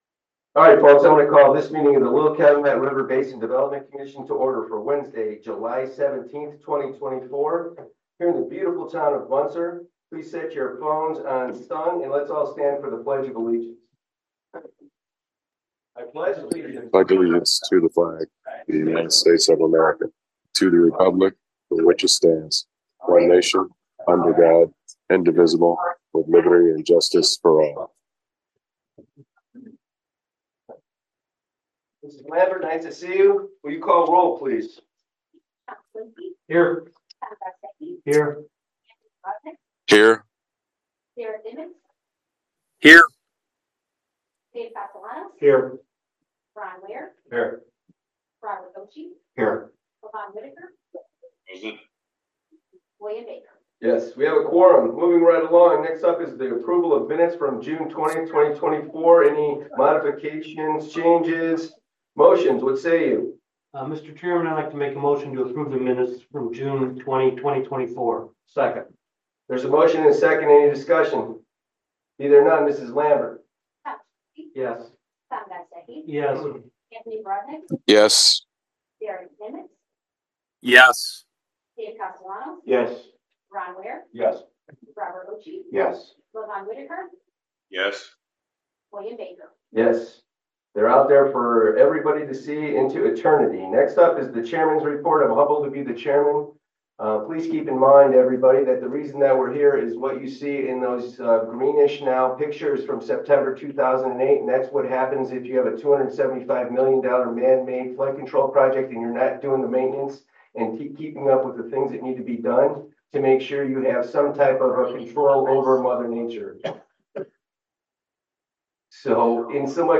12/18/2024 Public Meeting